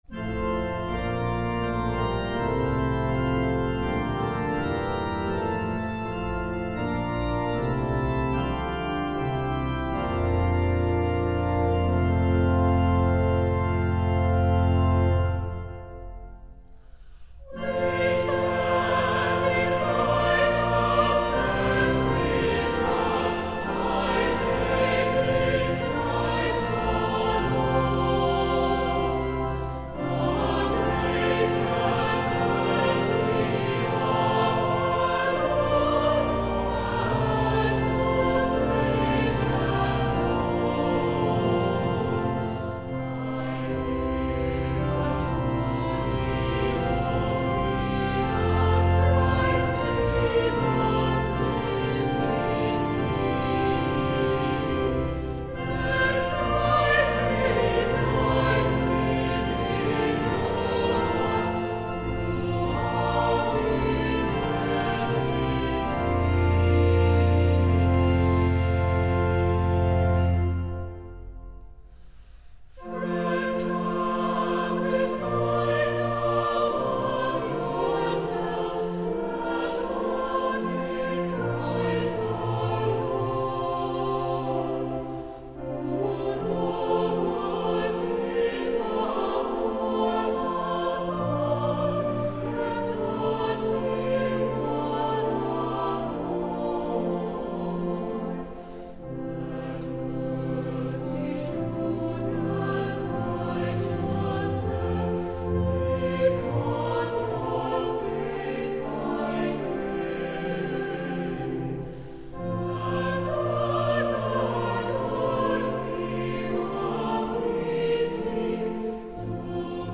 Includes optional Organ Choral reharmonization.